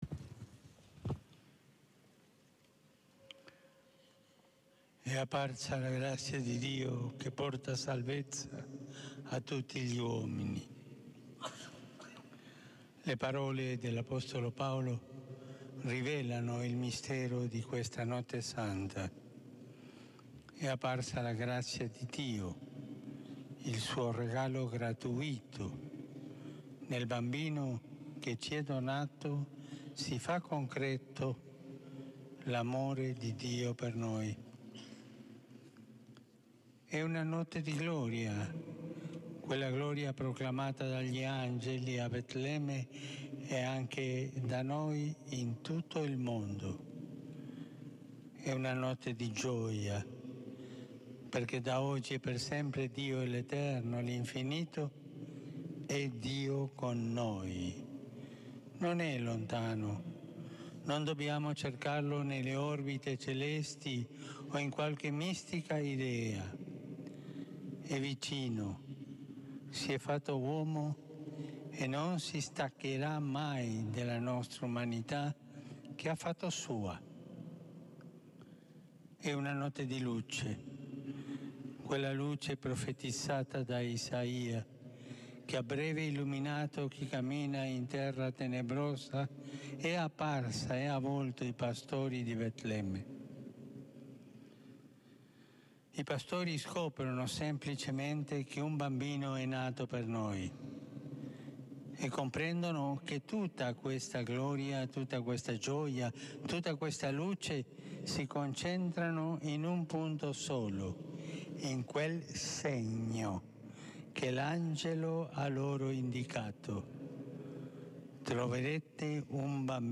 l'omelia di papa Francesco della Notte di Natale 2016